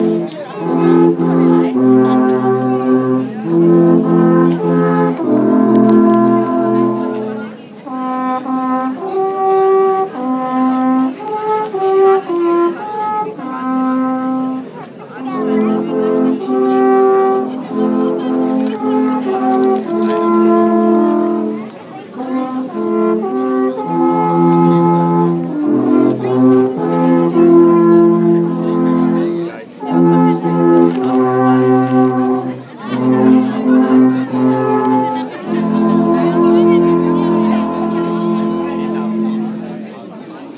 Alphorn